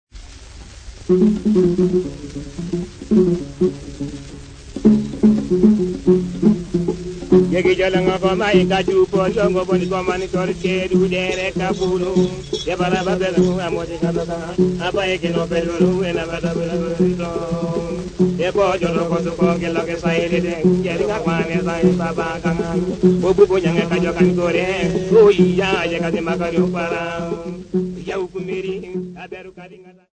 Teso men
Folk Music
Field recordings
Africa, Sub-Saharan
sound recording-musical
Indigenous music